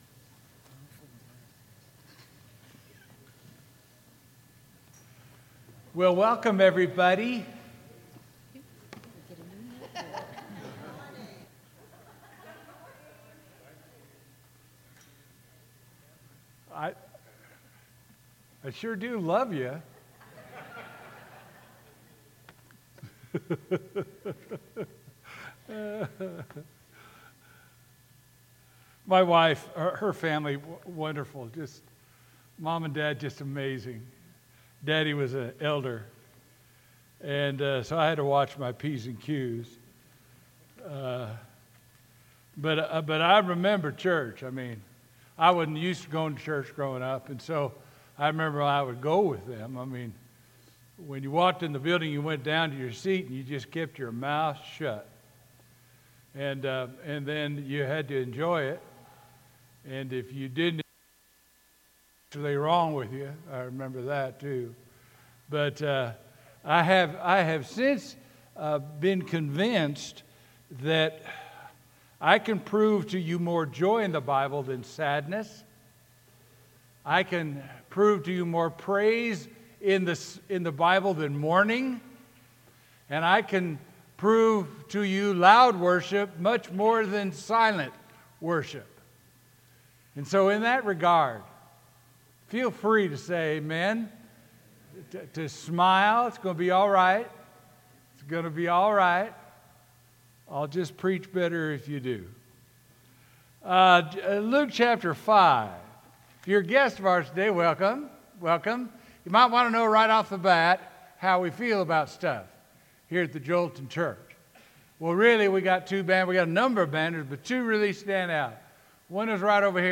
Sermon: Not Safe But Saved
sermon-october-2nd-2022.mp3